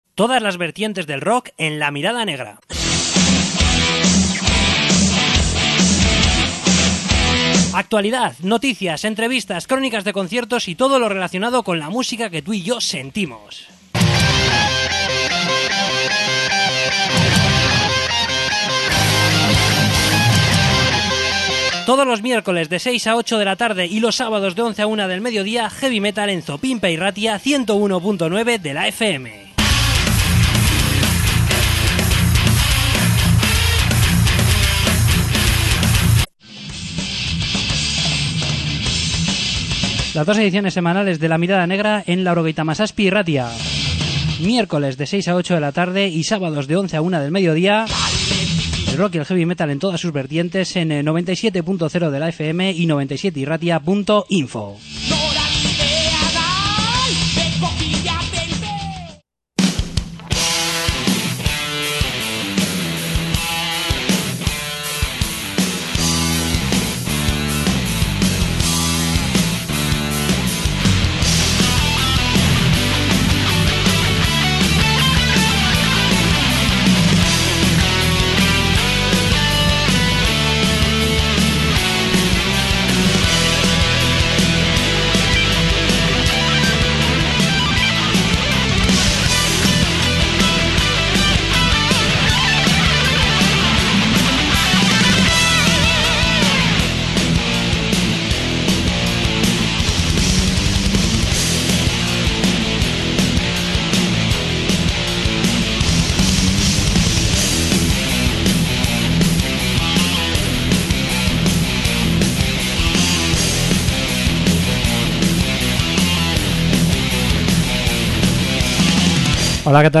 Entrevista con Angelus Apatrida
Entrevista con Ad Eternum